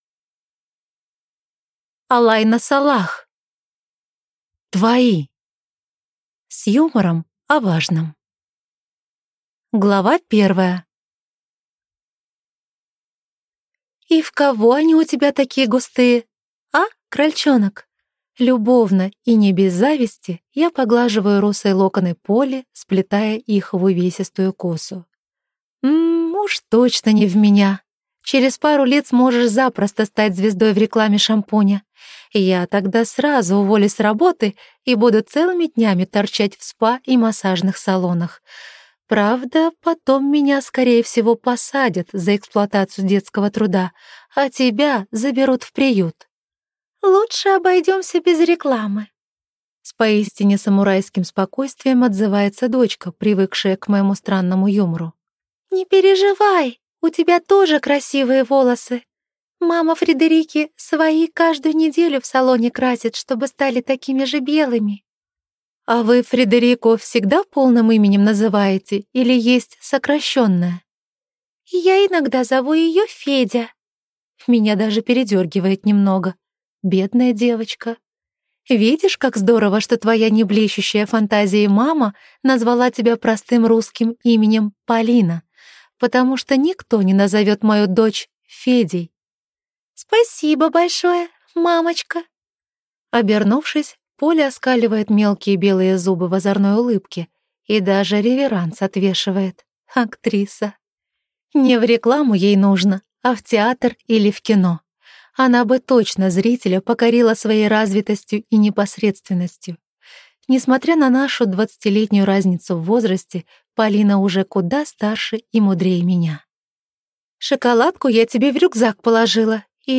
Аудиокнига Твои | Библиотека аудиокниг
Прослушать и бесплатно скачать фрагмент аудиокниги